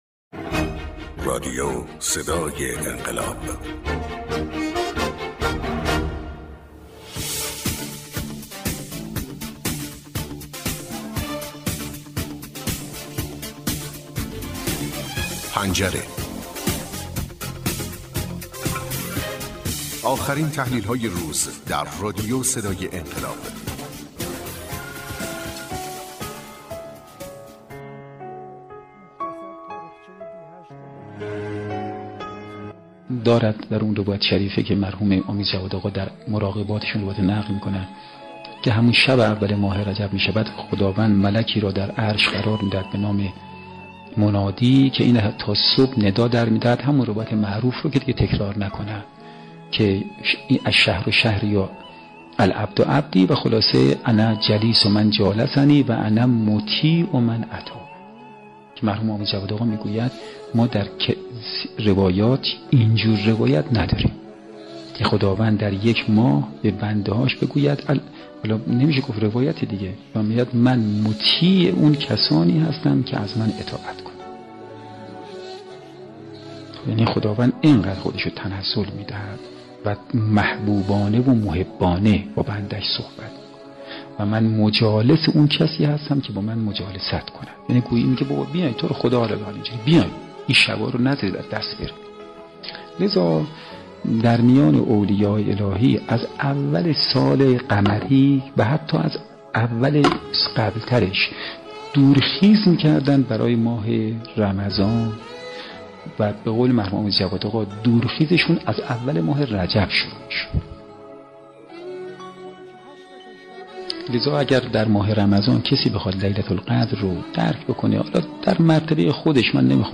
برچسب ها: بصیرت ، صدای انقلاب ، رجب ، نیایش ، دعا ، سخنرانی